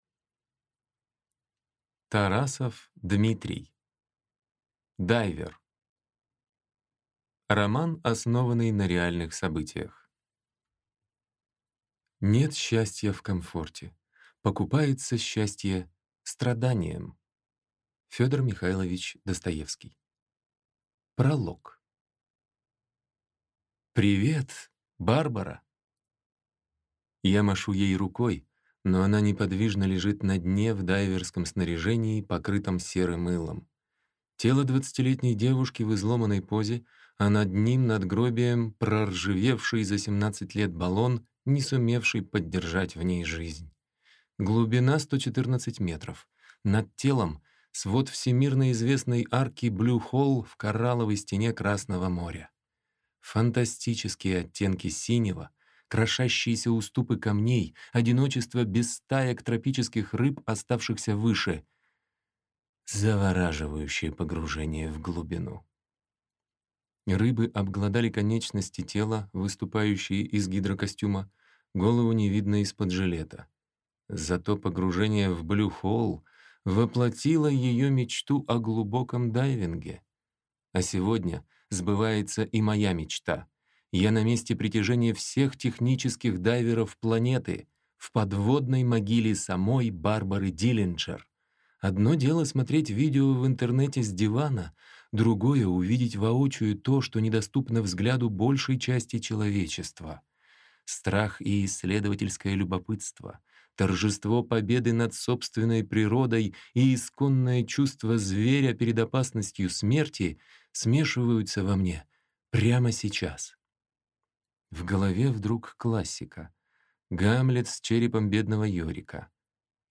Аудиокнига Дайвер | Библиотека аудиокниг